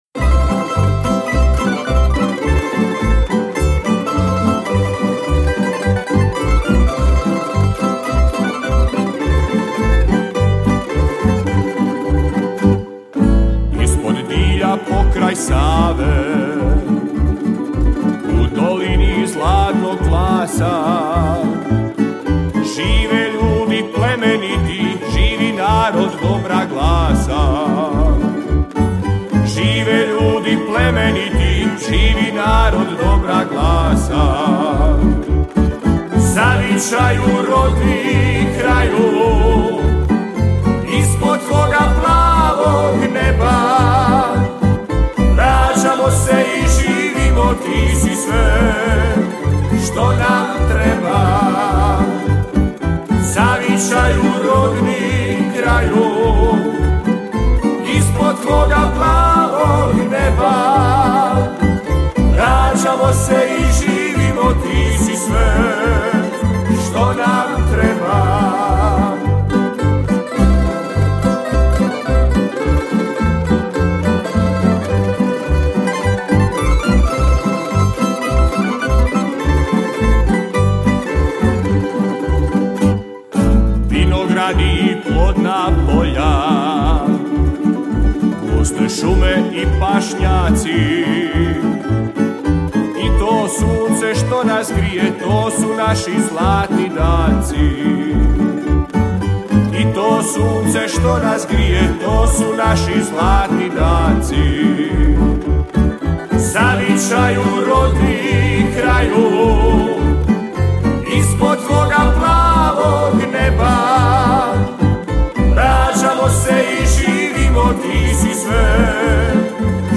Festival pjevača amatera
Zvuci tamburice odzvanjali su prepunom dvoranom vatrogasnog doma u Kaptolu do kasnih noćnih sati.